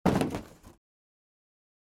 sfx-Back.ogg